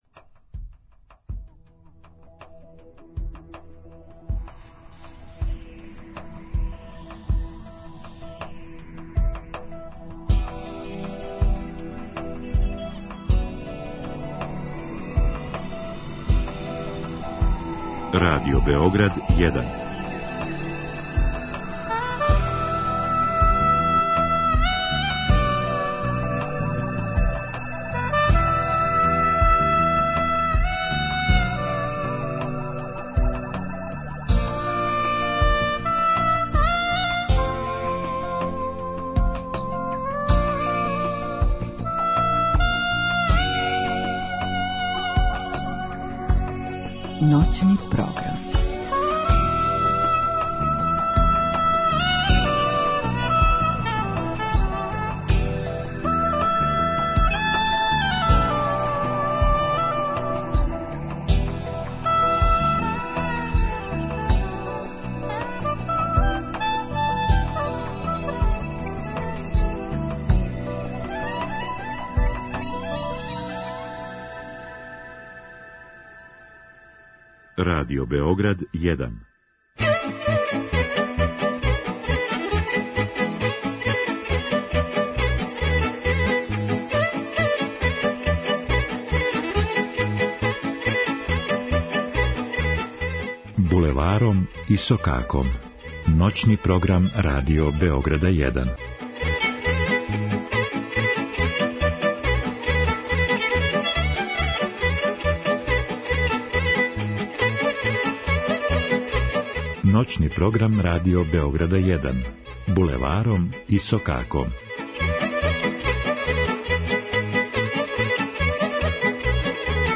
У емисији можете слушати изворну, староградску и музику у духу традиције.